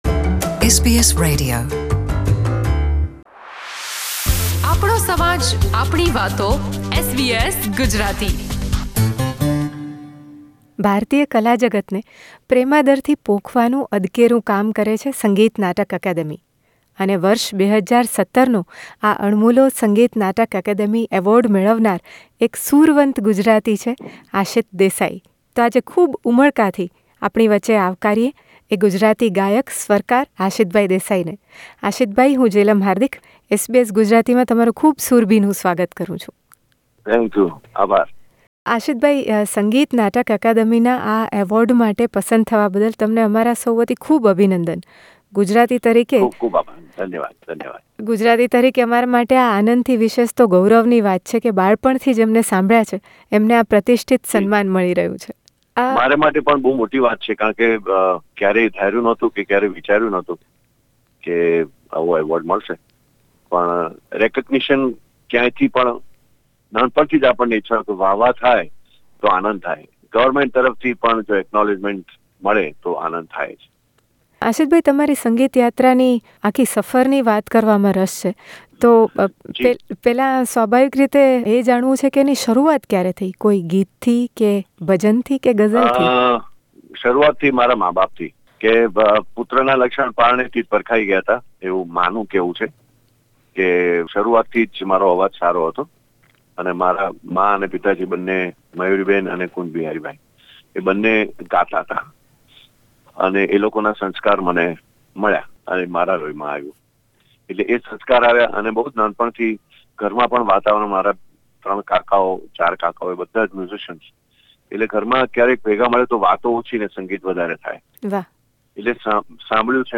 In this conversation before receiving the award, he shares some very interesting experiences from his musical career, including the first composition he created at the age of 17. He weaves some melodious songs in this first part of his conversation.